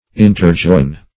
Search Result for " interjoin" : The Collaborative International Dictionary of English v.0.48: Interjoin \In`ter*join"\, v. t. [imp.